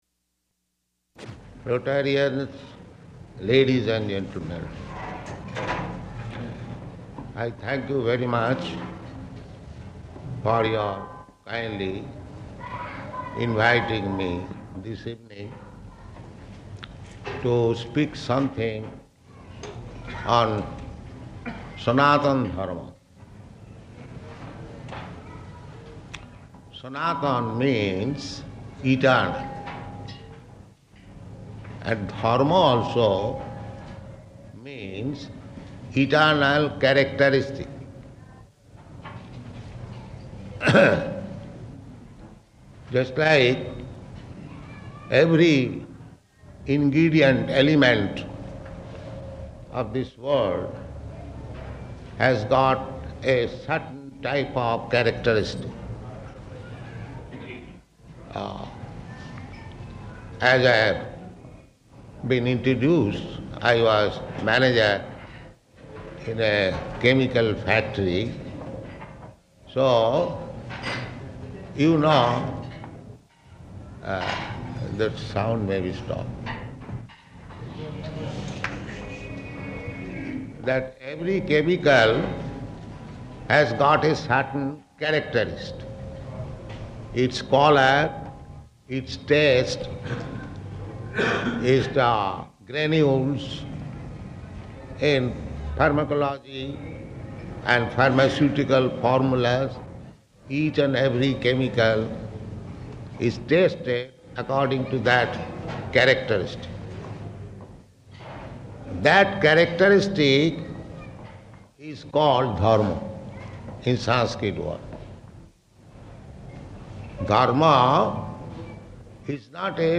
Rotary Club Lecture, Bhagavad-gītā 2.24
Type: Lectures and Addresses
Location: Ahmedabad